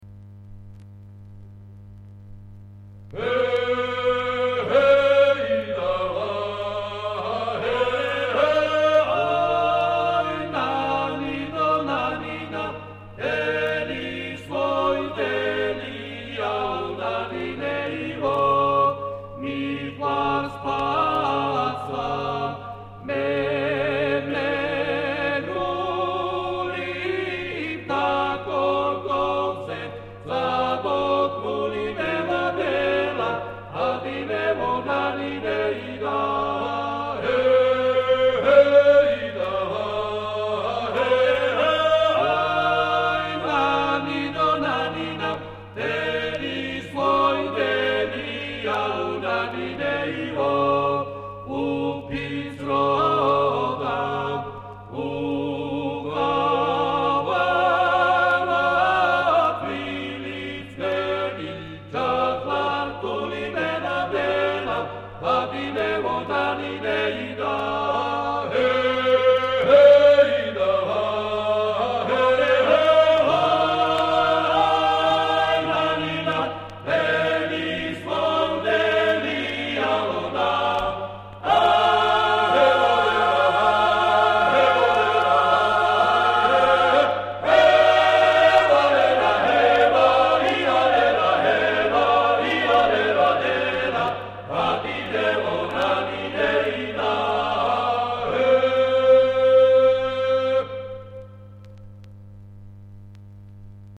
Keywords: ქართული ხალხური სიმღერა
(იმერული, მხედრული)